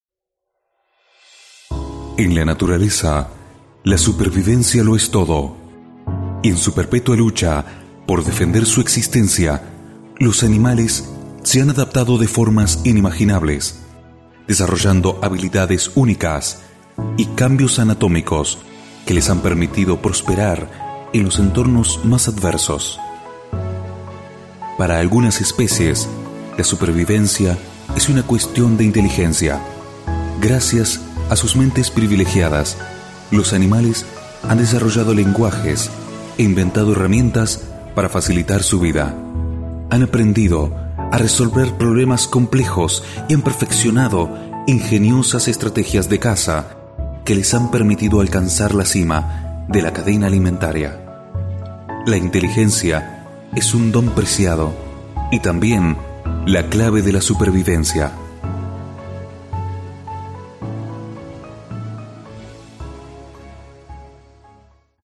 Mi voz puede variar en intensidad, de suave para lecturas y documentales, a más intensa para relatos, doblajes y publicidad.
spanisch Südamerika
Sprechprobe: Werbung (Muttersprache):
My voice can vary in intensity, from soft for readings and documentaries, to more intense for stories, dubs and publicity.
DocumentalMP3 (1).mp3